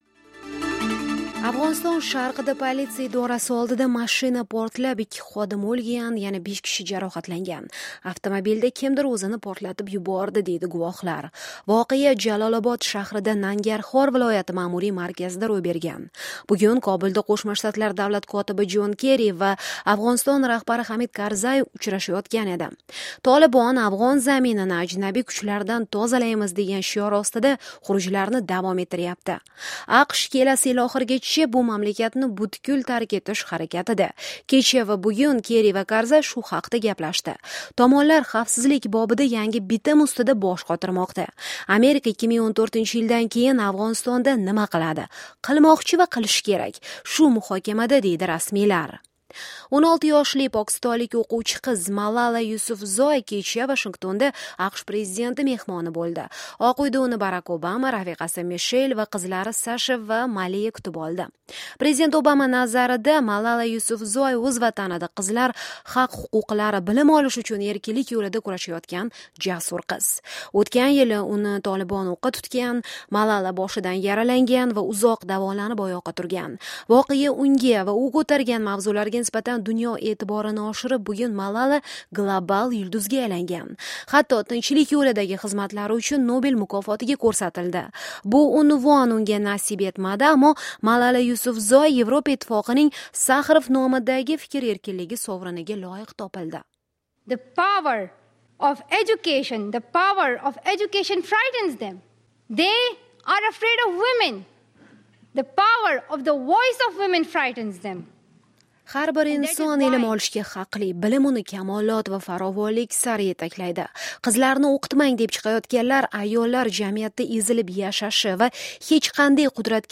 "Amerika Ovozi" xabarlari, 12-oktabr, 2013